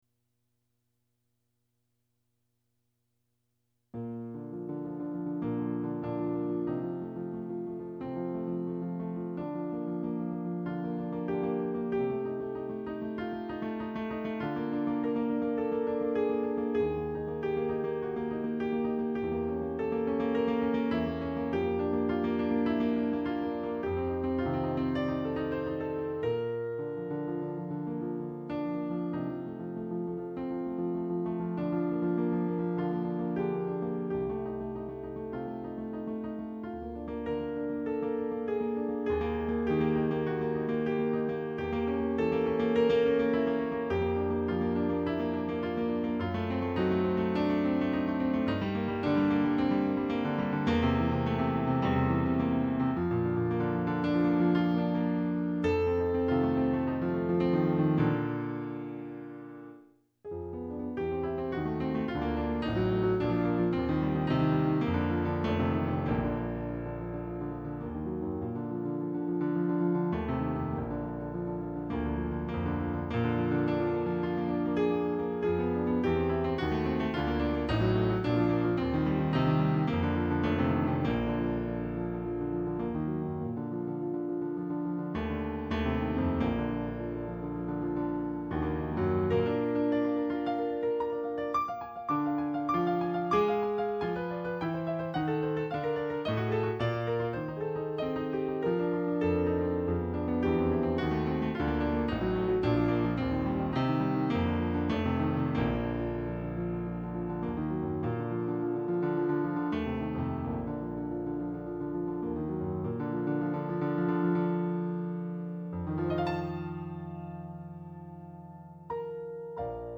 B flat major